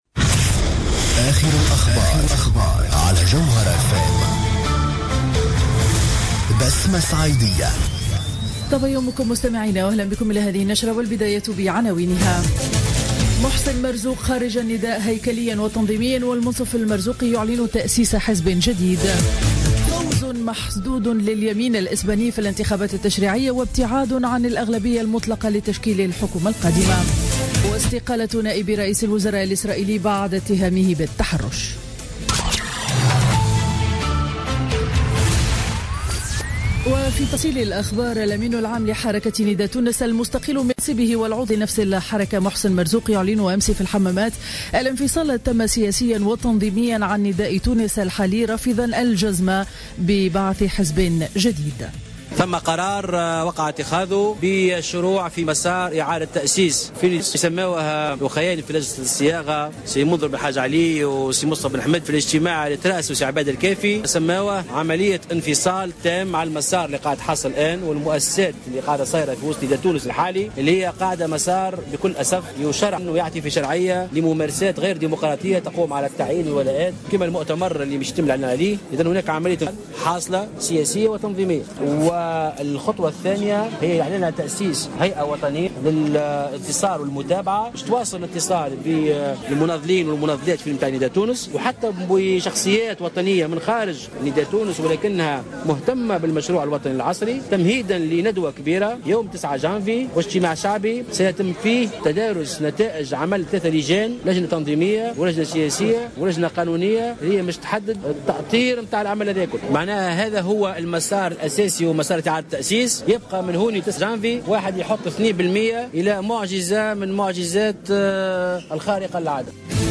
نشرة أخبار السابعة صباحا ليوم الاثنين 21 ديسمبر 2015